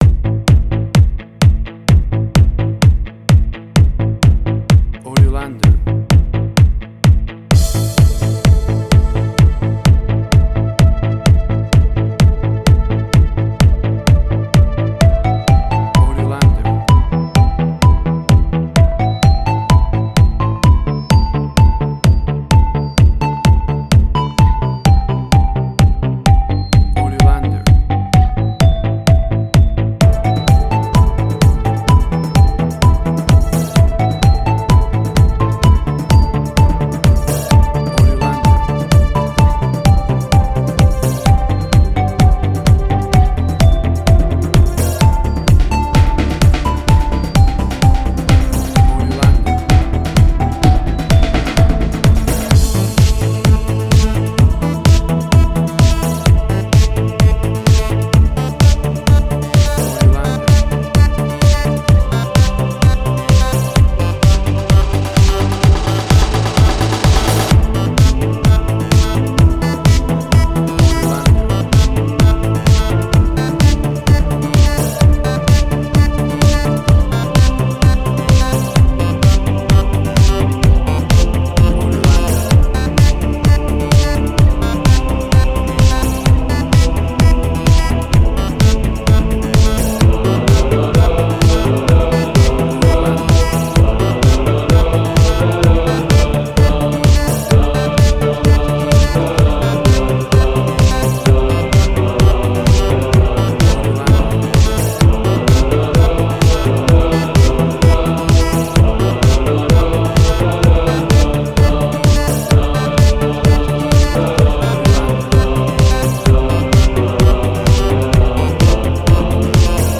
Jewish Techno Trance.
Tempo (BPM): 128